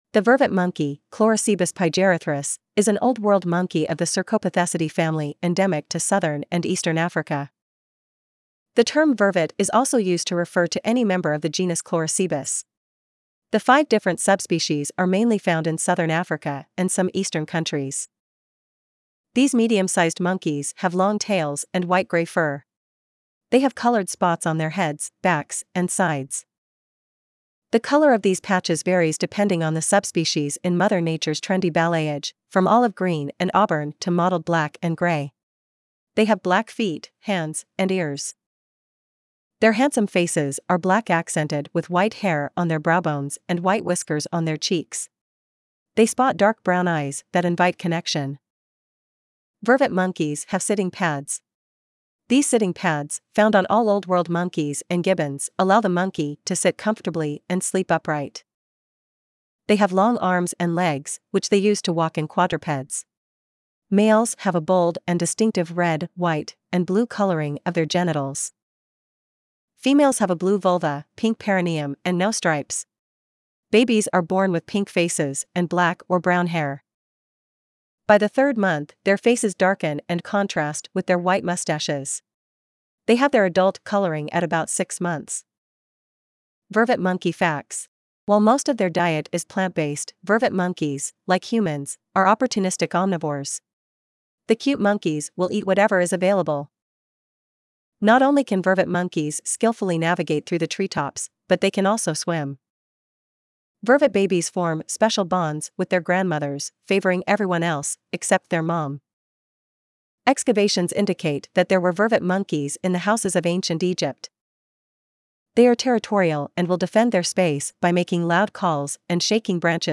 Vervet Monkey
Vervet-Monkey.mp3